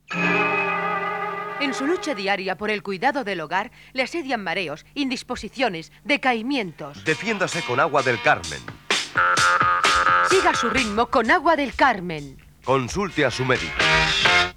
Anunci d'Agua del Carmen